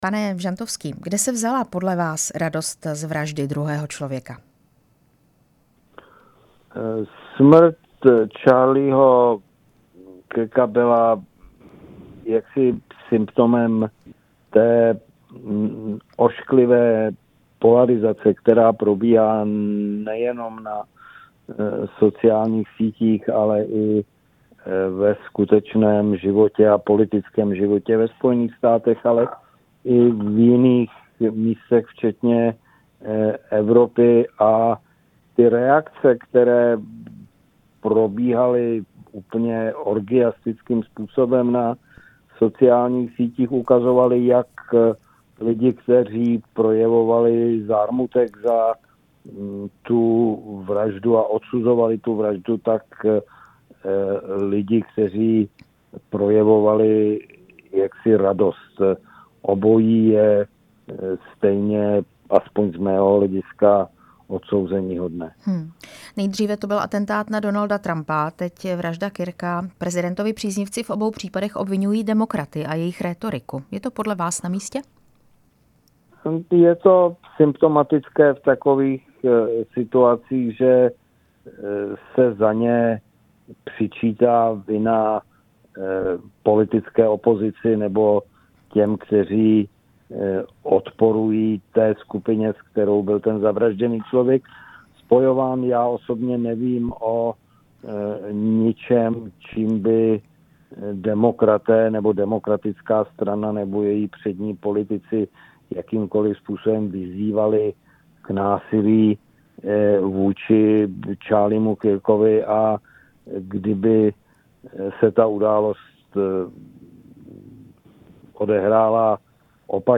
Příznivci prezidenta Donalda Trumpa z toho viní opoziční demokraty. Ve vysílání Radia prostor teď vítám Michaela Žantovského, zahraničně politického poradce prezidenta Petra Pavla a někdejšího velvyslance ve Spojených státech, Británii a taky v Izraeli.
Rozhovor s Michaelem Žantovským, bývalým velvyslancem v USA, Británii a Izraeli